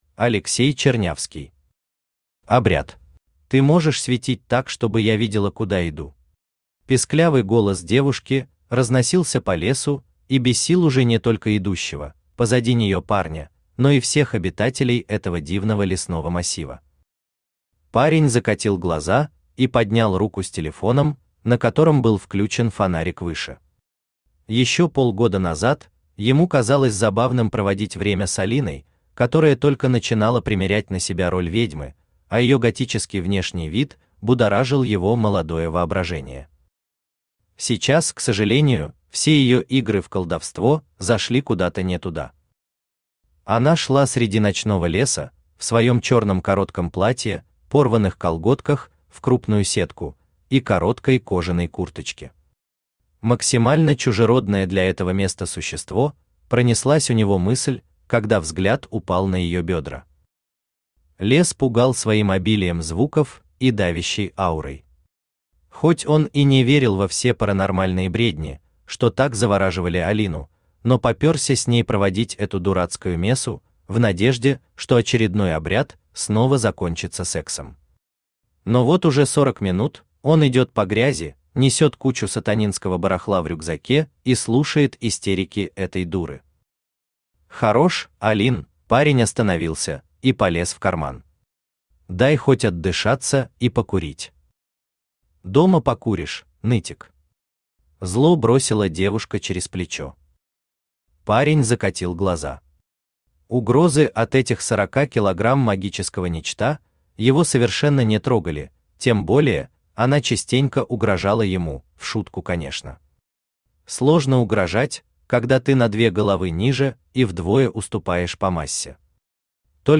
Аудиокнига Обряд | Библиотека аудиокниг
Aудиокнига Обряд Автор Алексей Андреевич Чернявский Читает аудиокнигу Авточтец ЛитРес.